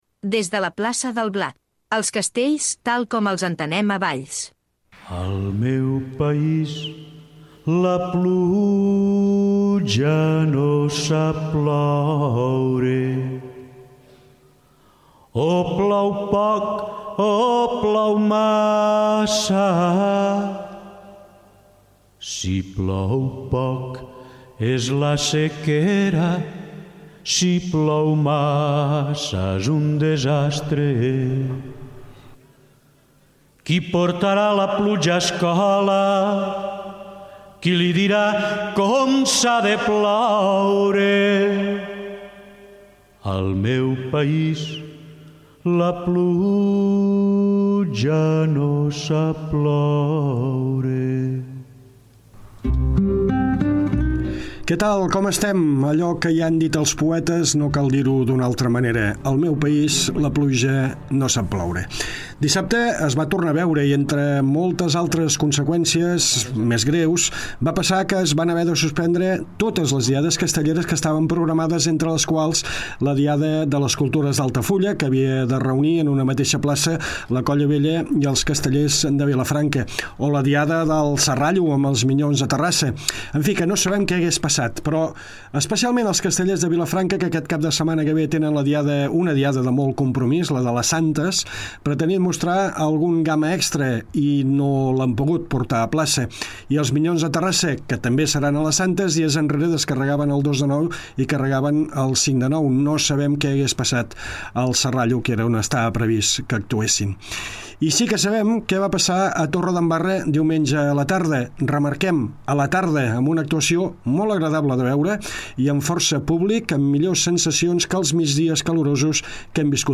Cinquena edició de Des de la Plaça del Blat, el programa que explica els castells tal com els entenem a Valls. Tertúlia